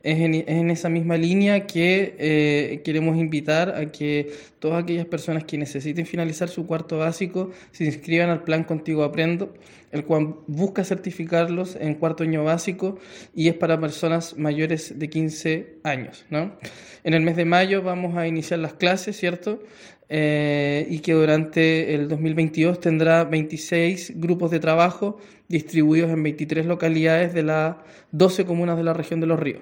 Según el Mineduc, en las 23 localidades de las 12 comunas de la región de Los Ríos se realizarán las clases a través de 26 grupos de trabajo, desde mayo hasta diciembre, con el fin de que las personas desarrollen competencias básicas de lenguaje y de matemáticas, como lo expresó el seremi de Educación, Juan Pablo Gerter.
seremi-juan-pablo-gerter.mp3